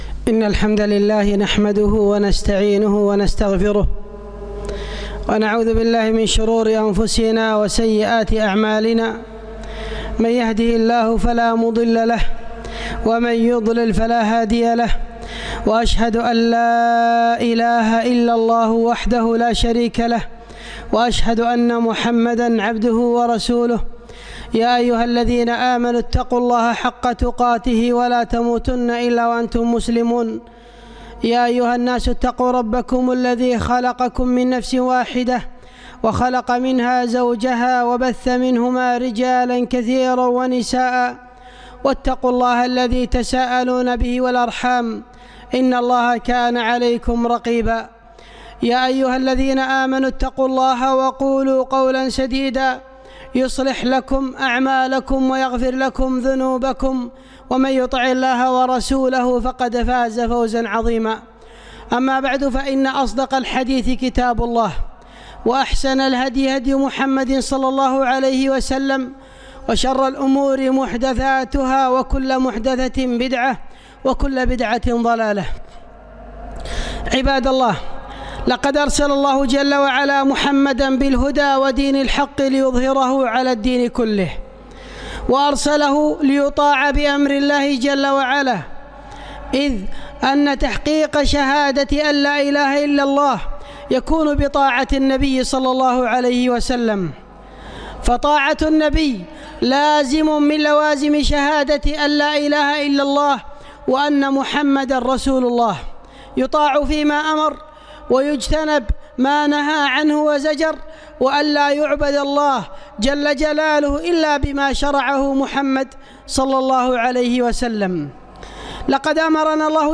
خطبة - لتبيّن للناس ما نزّل إليهم